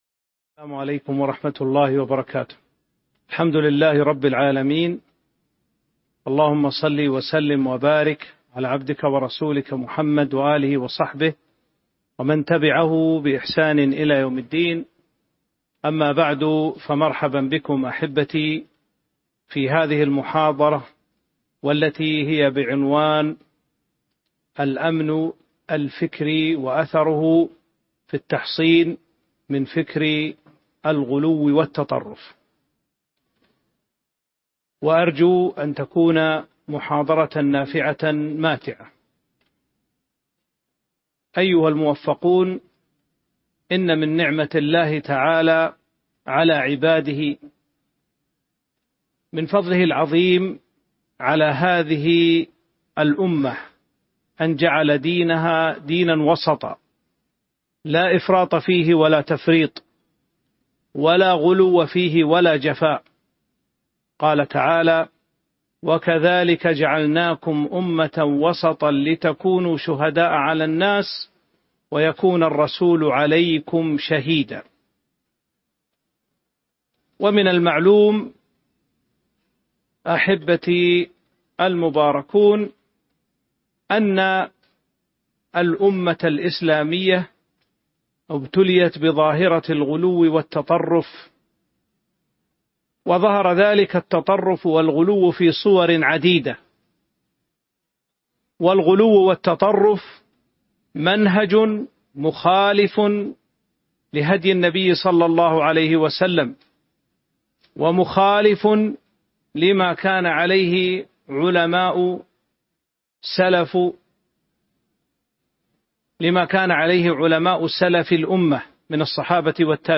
تاريخ النشر ١٥ محرم ١٤٤٧ هـ المكان: المسجد النبوي الشيخ